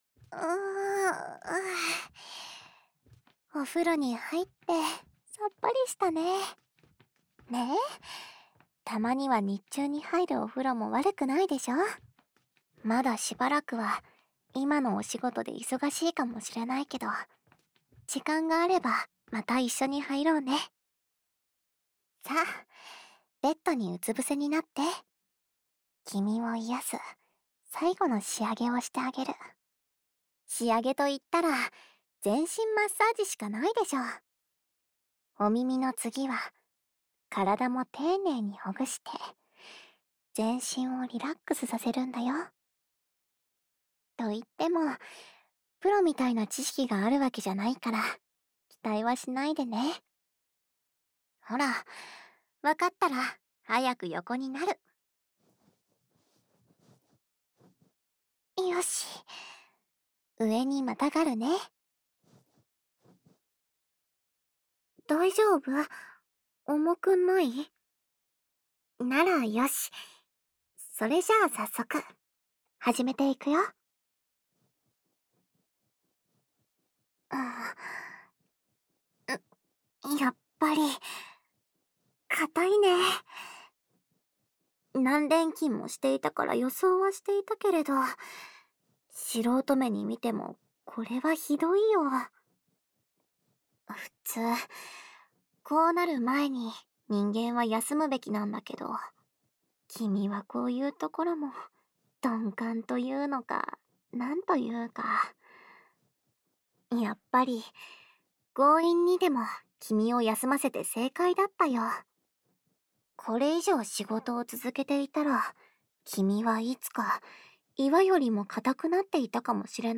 纯爱/甜蜜 温馨 治愈 掏耳 环绕音 ASMR 低语
el97_08_『最后再做个全身按摩为你舒缓疲劳』（全身按摩）.mp3